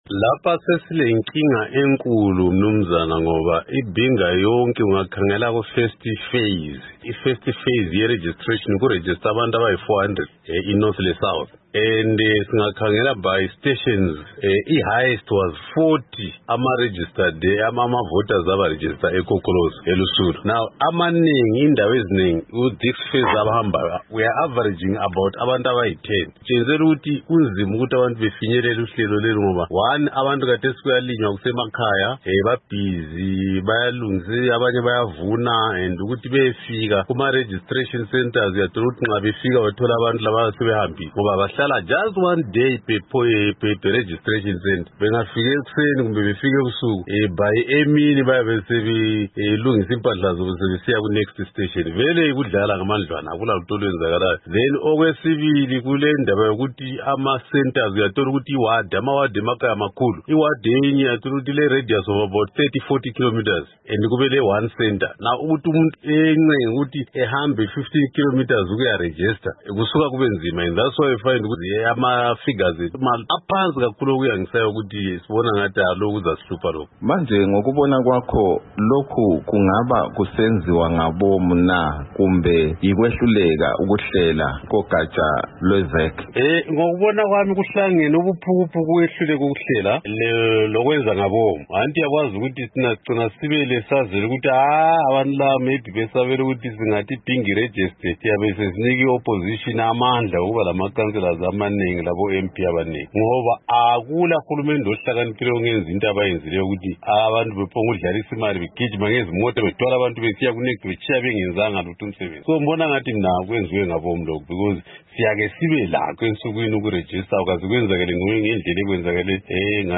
Ingxoxo loMnu. Joel Gabhuza